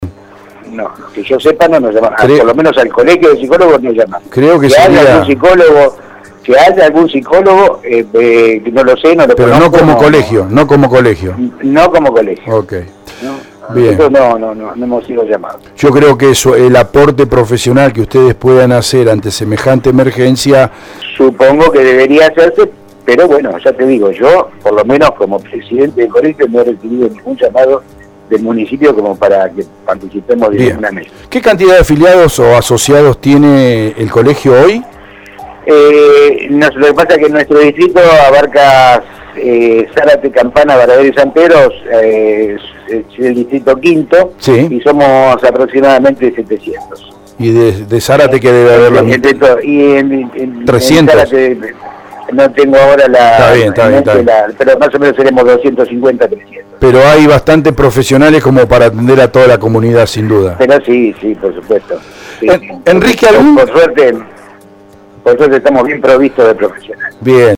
Interesante entrevista de este martes